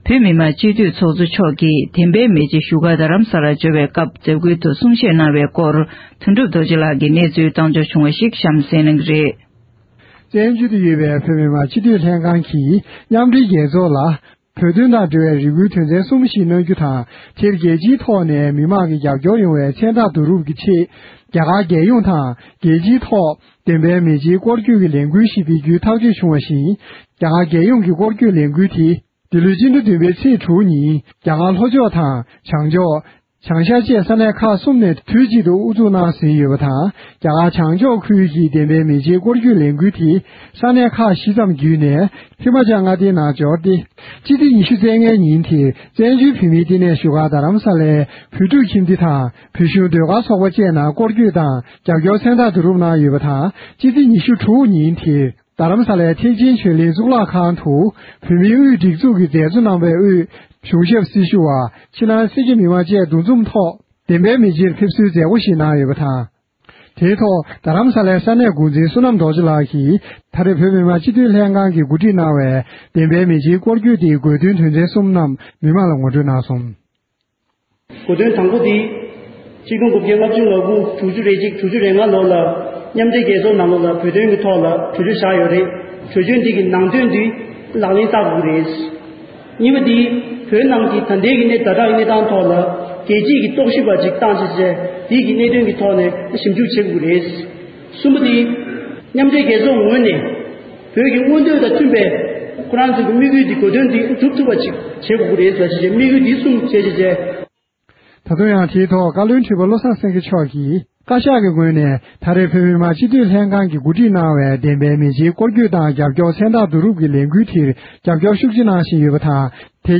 བཀའ་ཁྲི་དང་སྤྱི་འཐུས་ཚོགས་གཙོ་མཆོག་གིས་བདེན་པའི་མེ་ལྕེ་སྐོར་སྐྱོད་ཀྱི་མཛད་སྒོར་གསུང་བཤད་གནང་བ།
སྒྲ་ལྡན་གསར་འགྱུར། སྒྲ་ཕབ་ལེན།